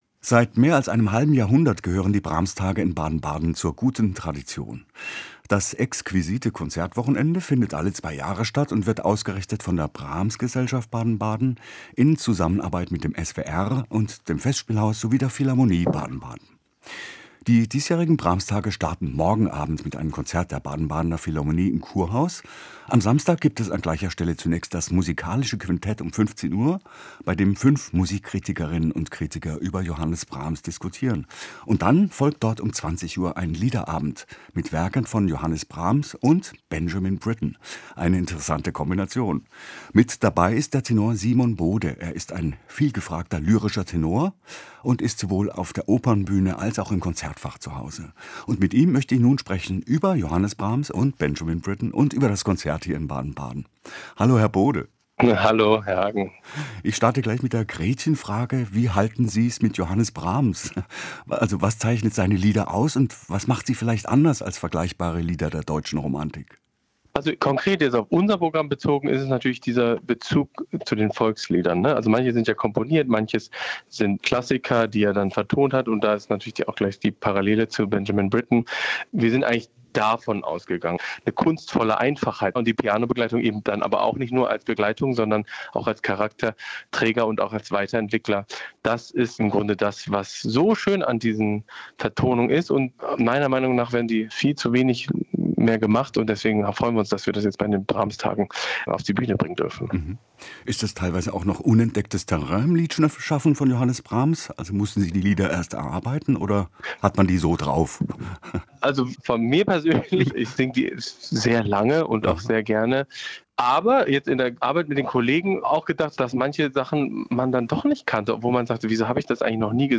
Das Interview führte
Interview mit